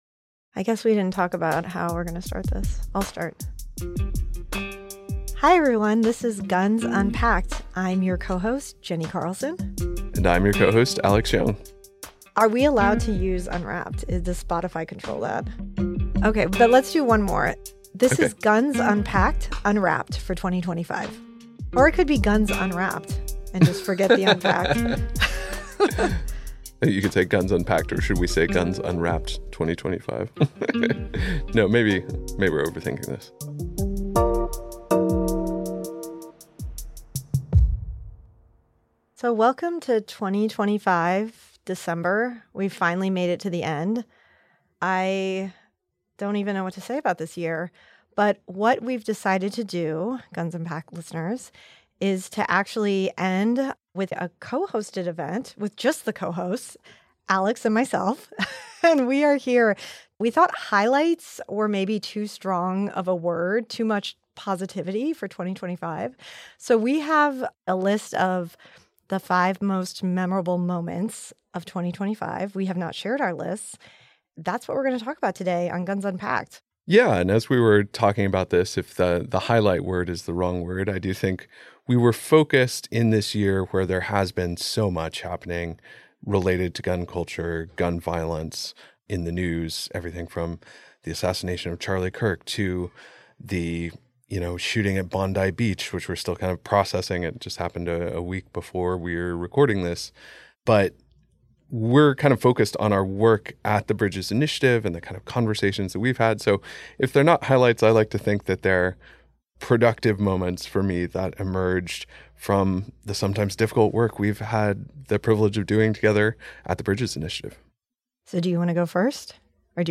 In a conversation that ranges from the snarky to the emotional, they take on issues including the algorithmic censorship of gun-related content online to the state of gun studies as a field to the ubiquity of gun-related trauma in the contemporary United States.